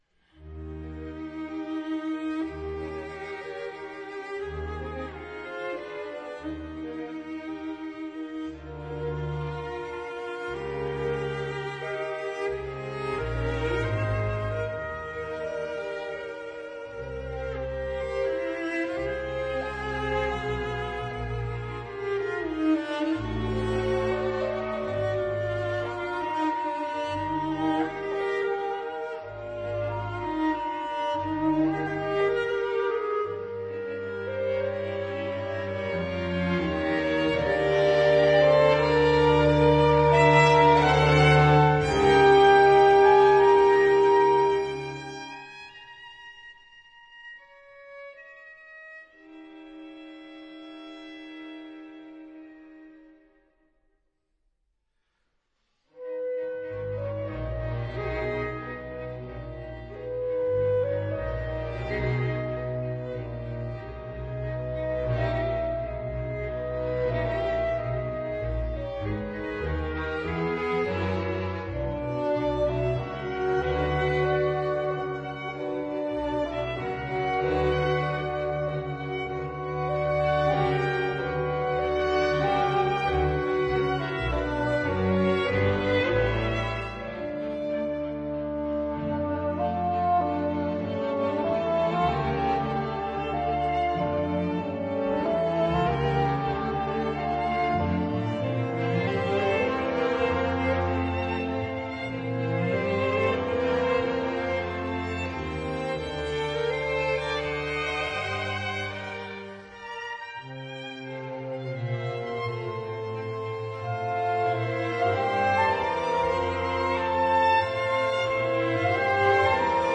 單簧管、低音管、法國號、兩把小提琴、中提琴、大提琴和低音提琴。
反而是收縮，像個二重奏、三重奏地親密勾勒。
但是那種生動與親密，毫不遜色於法國演奏家。